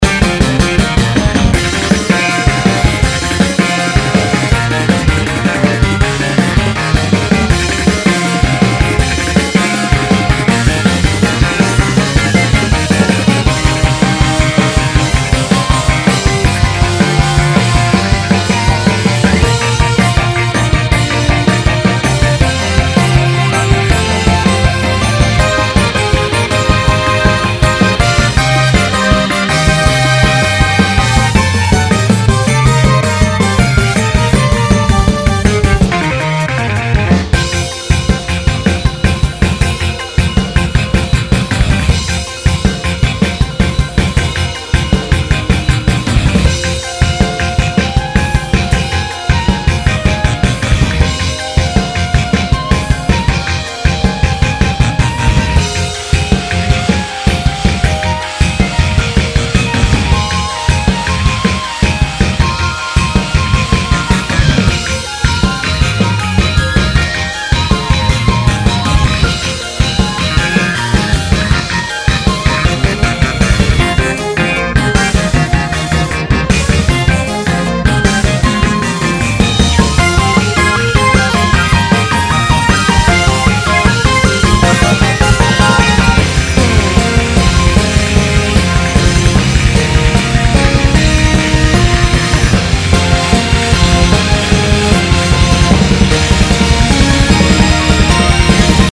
■6/26のAメロ後半～オリジナル追加パート直し
やっと全体の音にまとまりが出てきた。
1:26のところで音痴な音を出してるのは書き出しバグなんだけど面白いからここではこのままあげておこう。